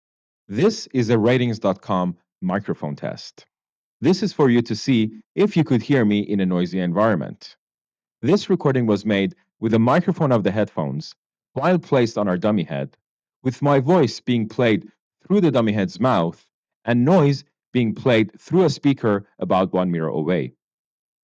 For headphones with microphones, we use the same voice sample to record a demonstration of the mic's sound by having it playback from a speaker located where a person's mouth is, and then use the headphones' microphone to capture the audio.
Audeze Maxwell Wireless boom mic system sounds without the introduction of noise.